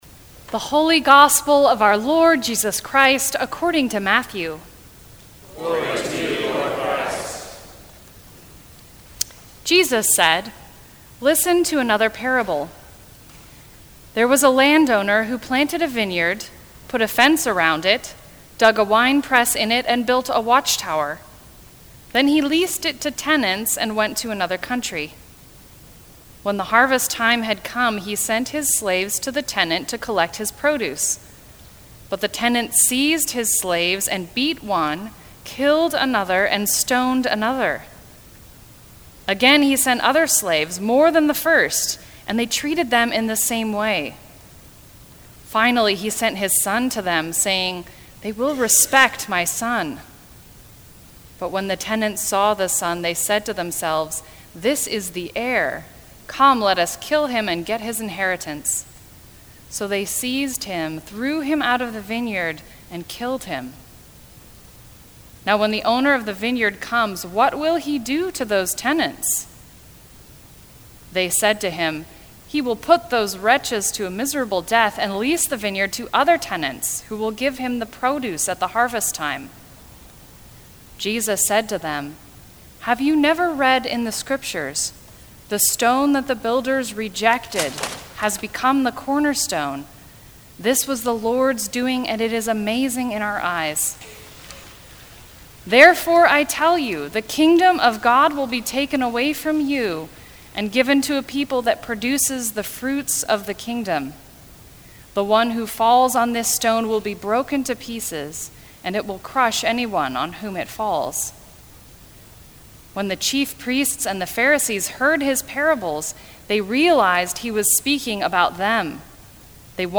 Eighteenth Sunday after Pentecost.
Sermons